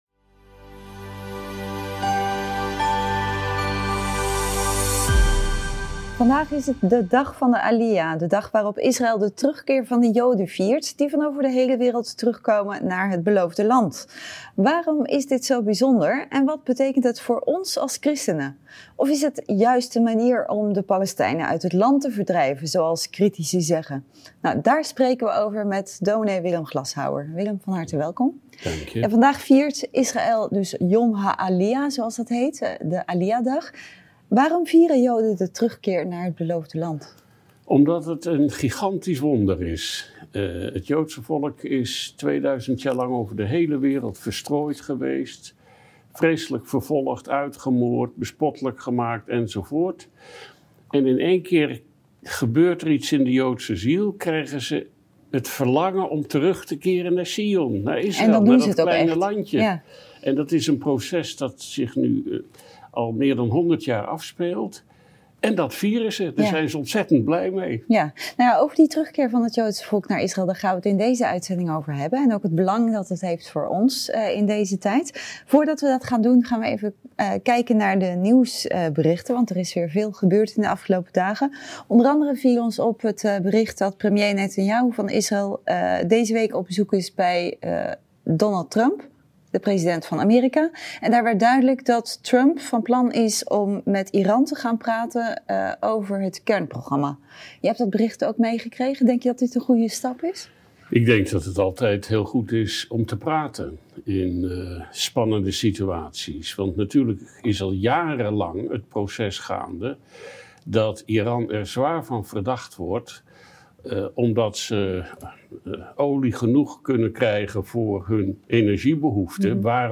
Daar spreken we over met dominee